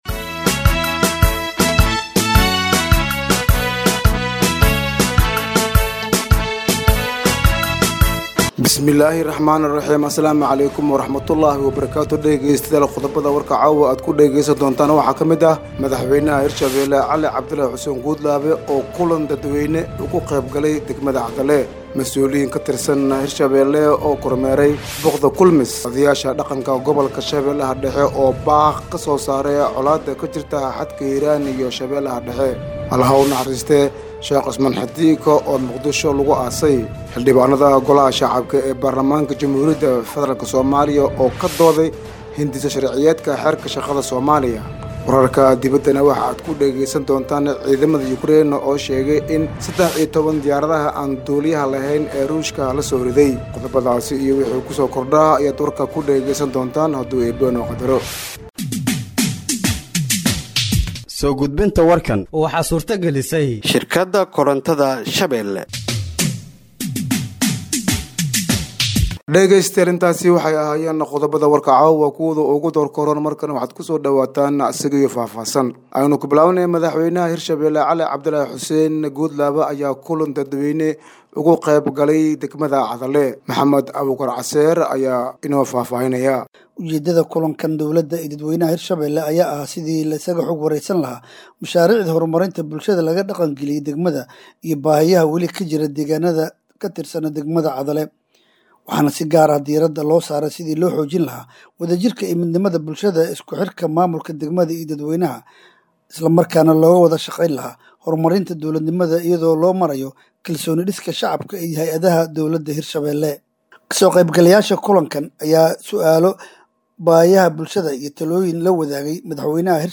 Warka habeenimo ee radiojowhar